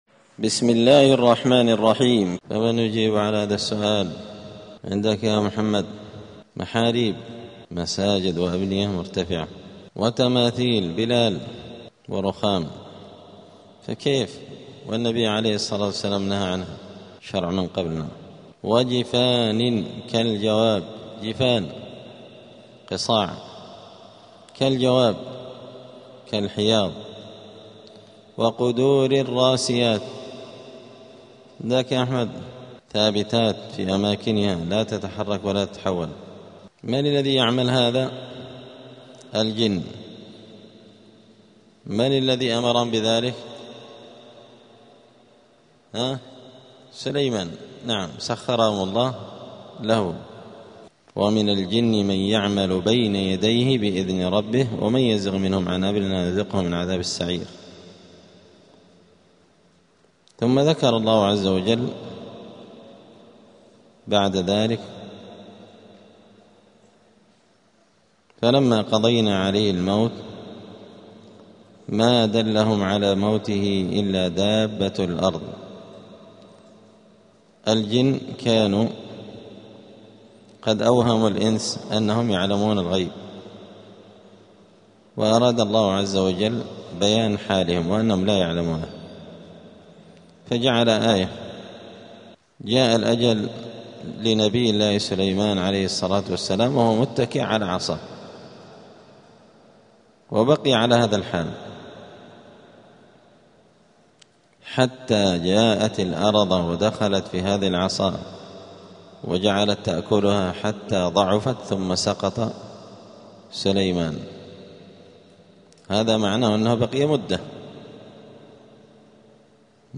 زبدة الأقوال في غريب كلام المتعال الدرس الرابع والثلاثون بعد الثلاثمائة (334)
دار الحديث السلفية بمسجد الفرقان قشن المهرة اليمن